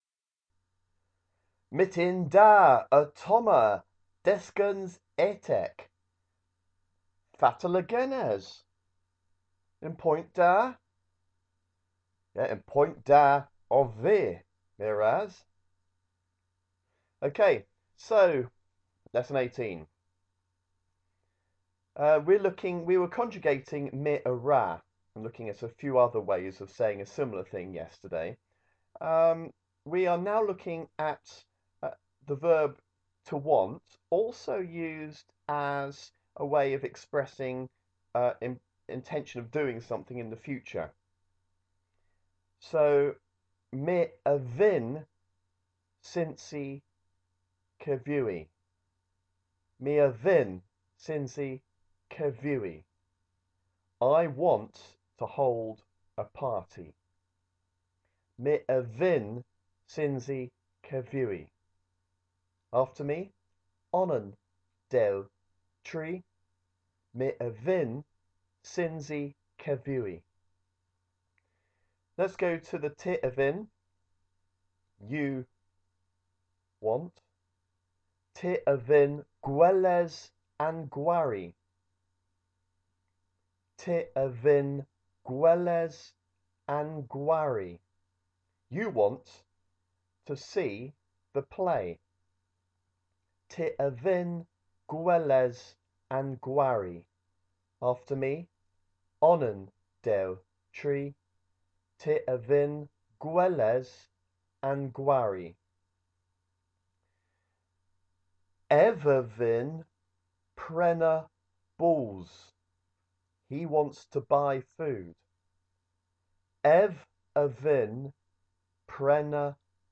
Cornish lesson 18 - dyskans etek